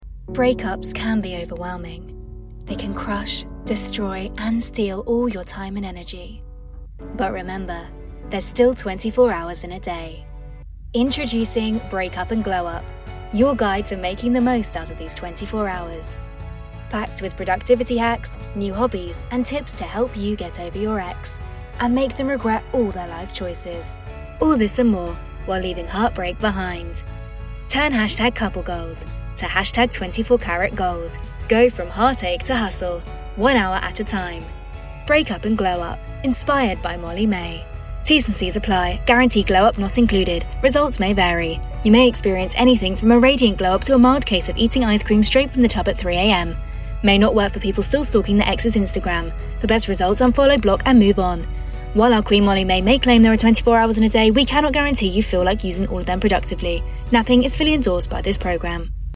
English (British)
Commercial, Young, Cool, Versatile, Friendly
Commercial